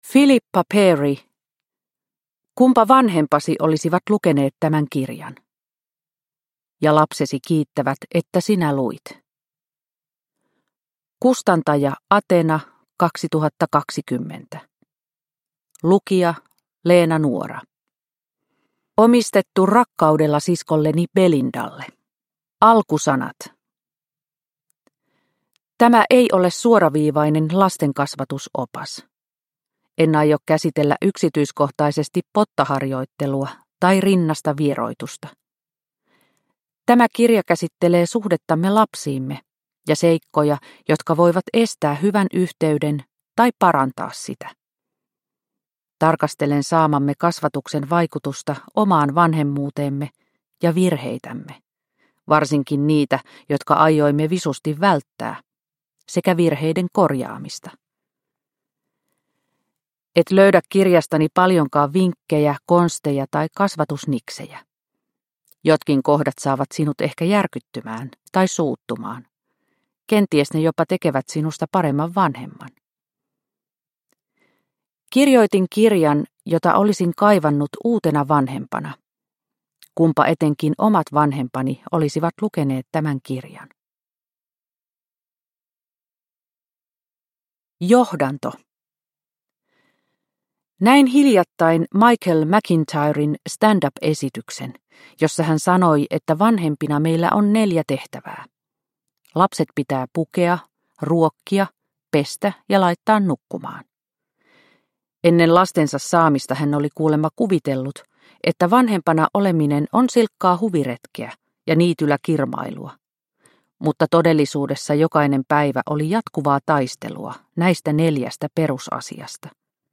Kunpa vanhempasi olisivat lukeneet tämän kirjan – Ljudbok – Laddas ner